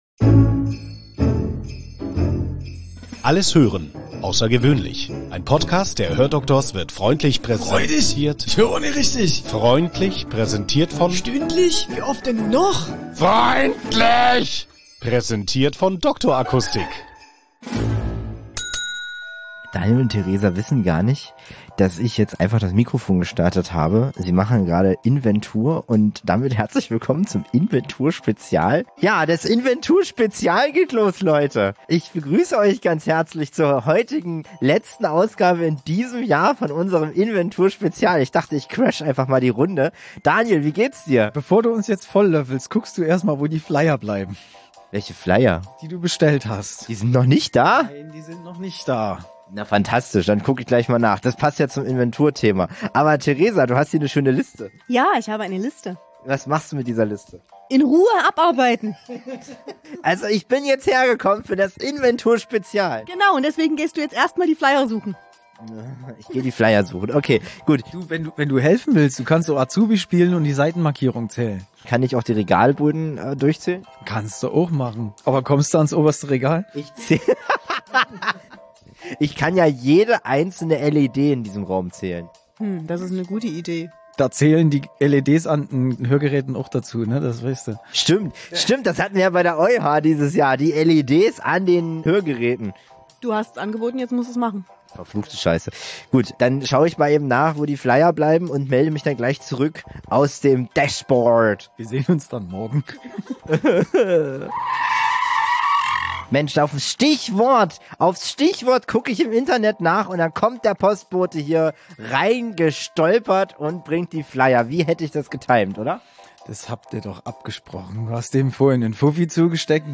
mitten aus dem Laden in Radebeul, zwischen Inventurlisten, leeren Kaffeetassen und der leisen Hoffnung, dass nichts mehr piept, was nicht piepen sollte.